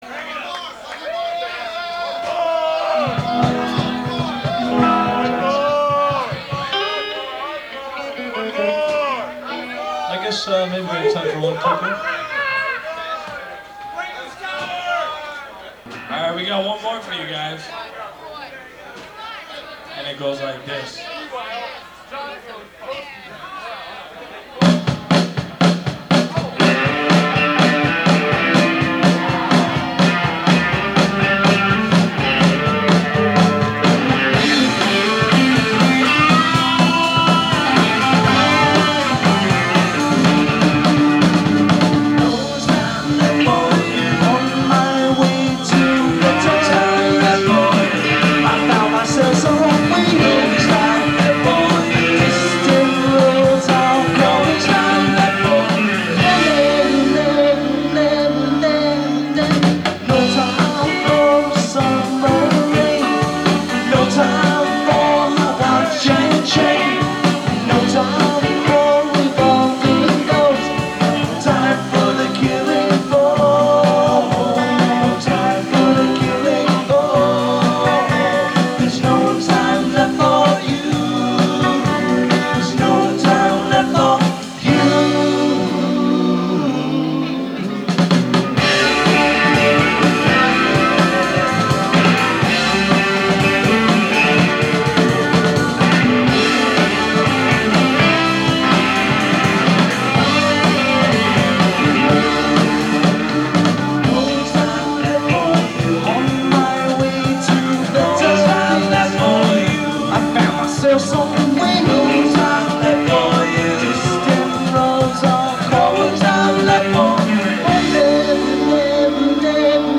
Live Recordings